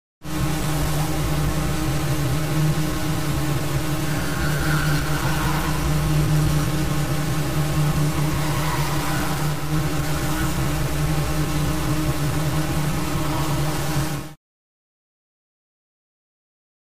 Alien Broadcast; Buzzing Radio Waves.